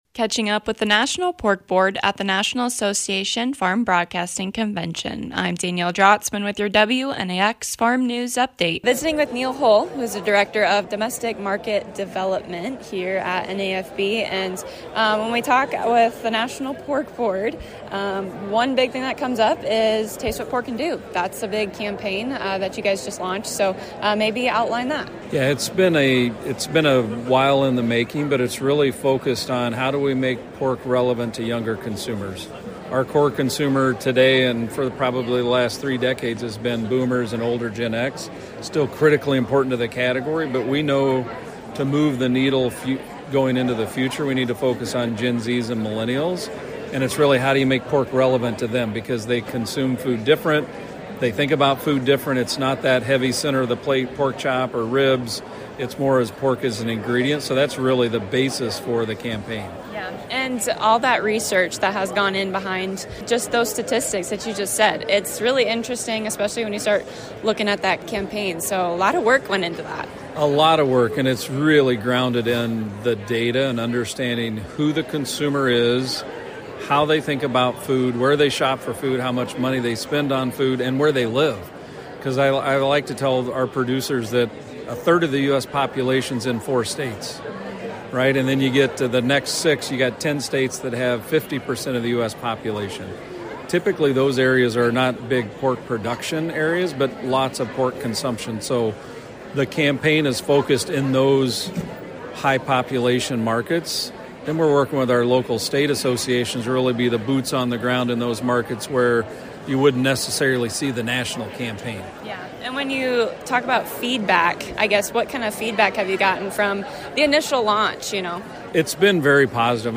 Catching up with the National Pork Board at the National Association Farm Broadcasting Convention in KC.